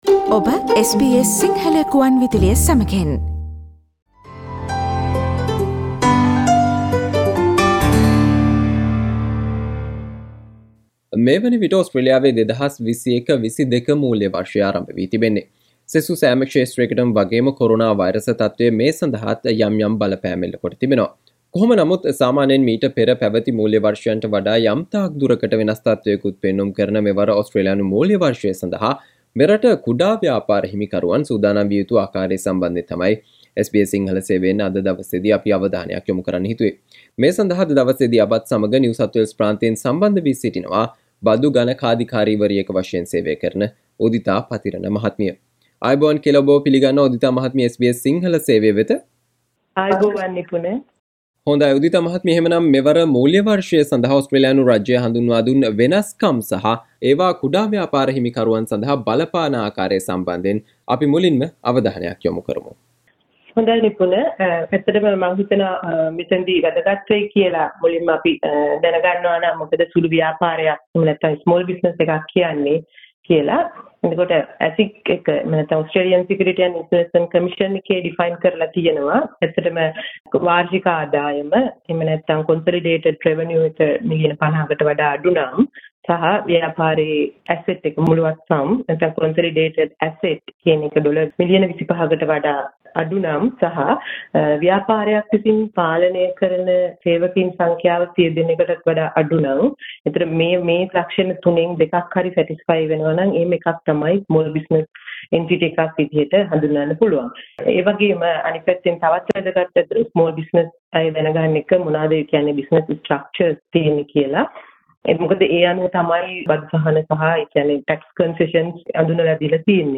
You can listen to the discussion conducted by SBS Sinhala on Things small business owners need to focus on this financial year